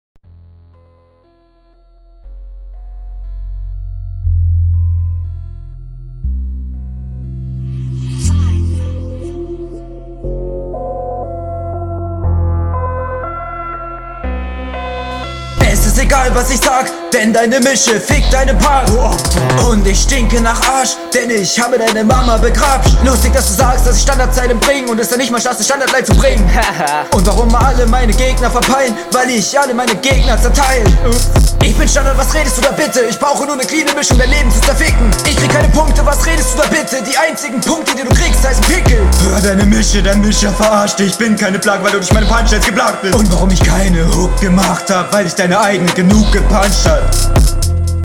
Flow: Kommst tatsächlich etwas besser auf dem Beat als dein Gegner, Stimmeinsatz gefällt mir gut.
Auffallend ist direkt die etwas bessere Mische.